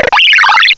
Add all new cries
cry_not_unfezant.aif